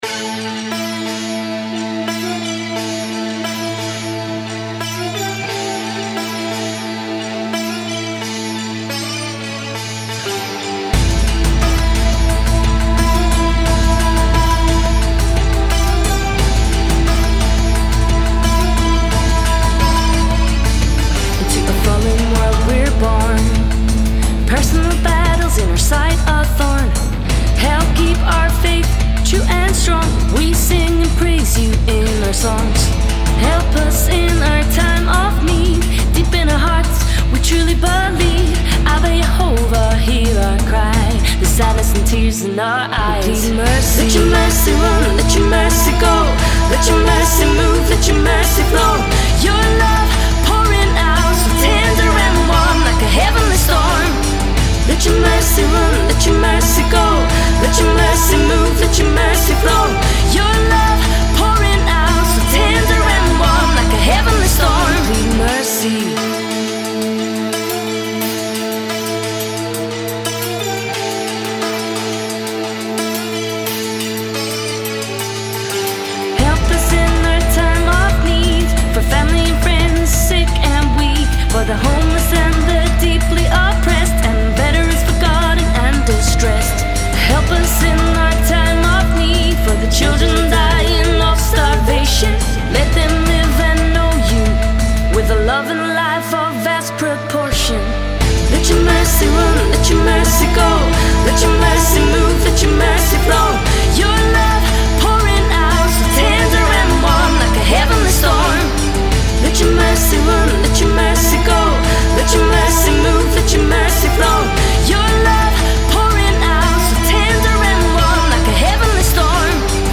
Lead & Background Vocals